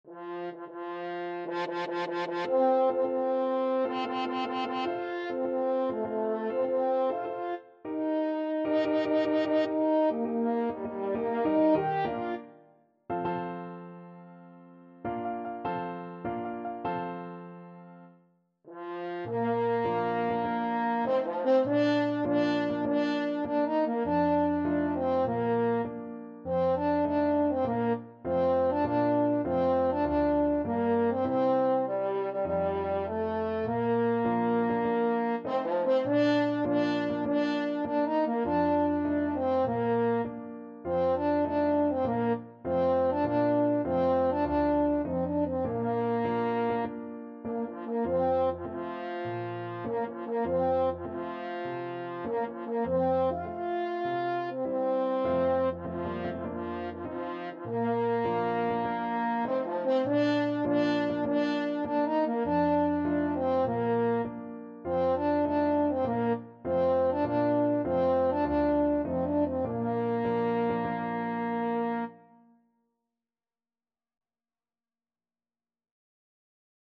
French Horn
Bb major (Sounding Pitch) F major (French Horn in F) (View more Bb major Music for French Horn )
Maestoso = c. 100
4/4 (View more 4/4 Music)
Classical (View more Classical French Horn Music)